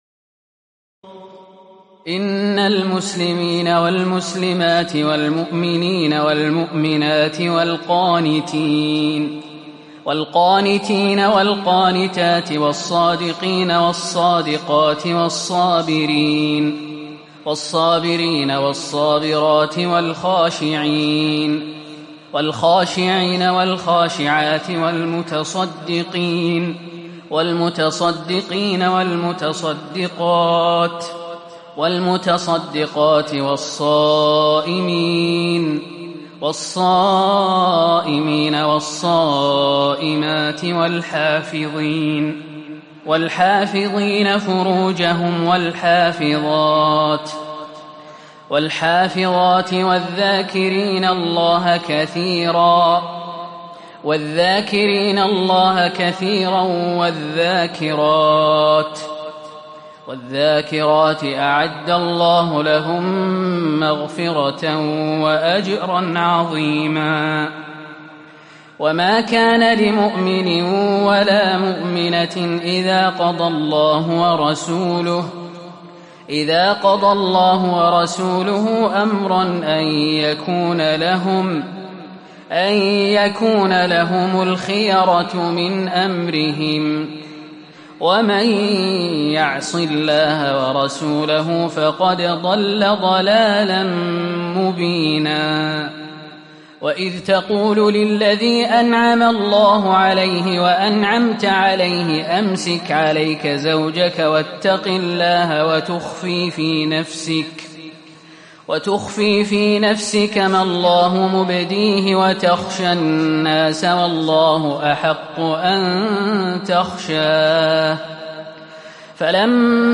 تراويح ليلة 21 رمضان 1438هـ من سور الأحزاب (35-73) وسبأ (1-23) Taraweeh 21 st night Ramadan 1438H from Surah Al-Ahzaab and Saba > تراويح الحرم النبوي عام 1438 🕌 > التراويح - تلاوات الحرمين